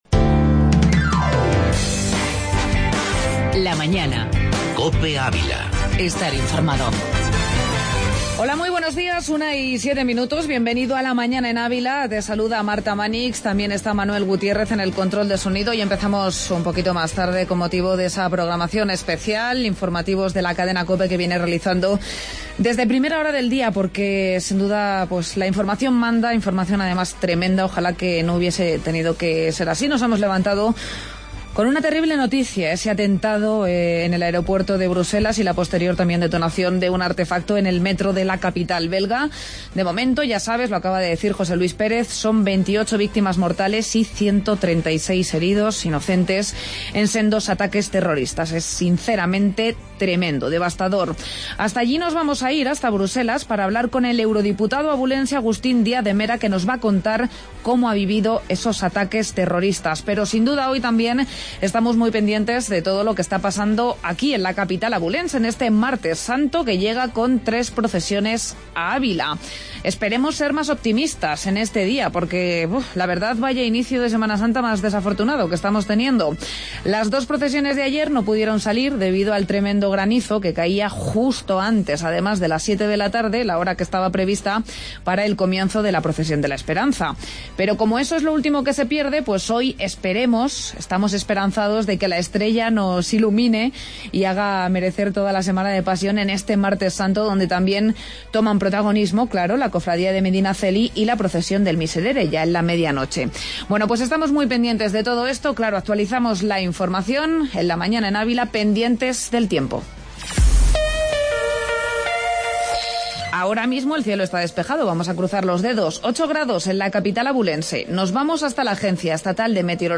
AUDIO: Entrevista Agustín Díaz de Mera desde Brúselas